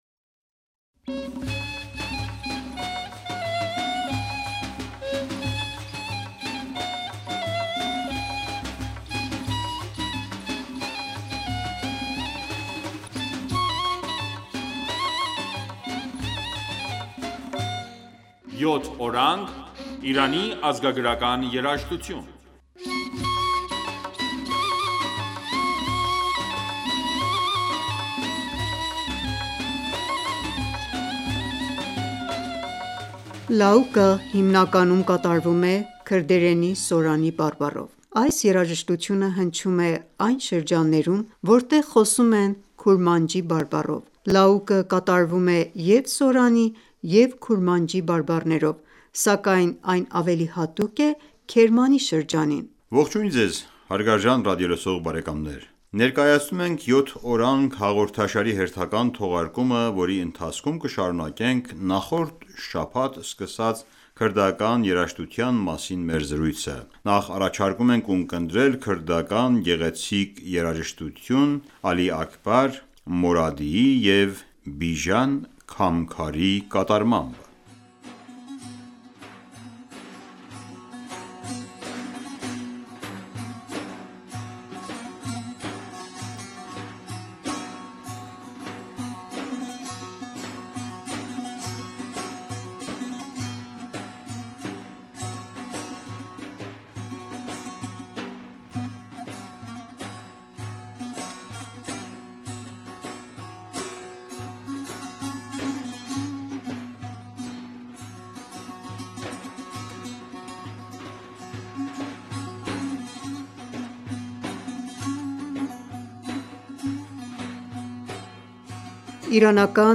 Ողջույն Ձեզ հարգարժան ռադիոլսող բարեկամներ։
Նախ առաջարկում ենք ունկնդրել քրդական գեղեցիկ երաժշտություն՝ Ալի Աքբար Մորադիի և Բիժան Քամքարի կատարմամբ։